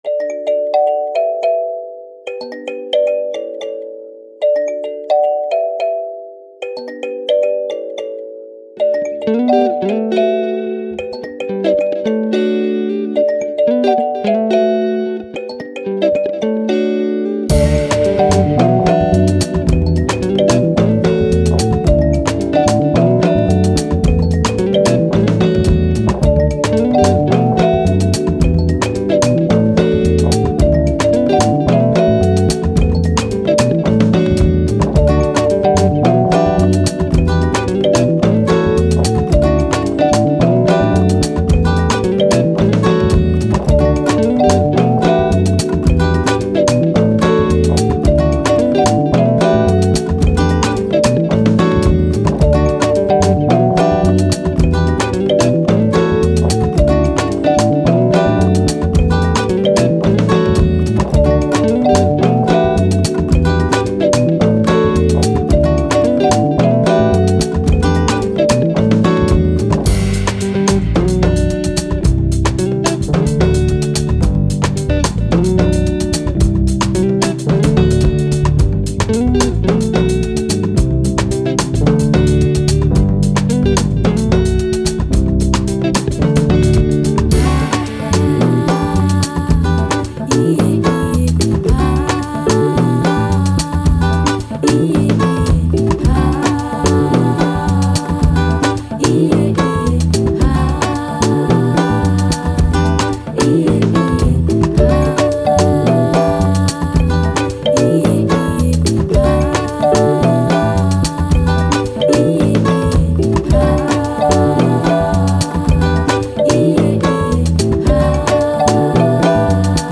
Afrobeat, Playful, Fun, Upbeat, Sun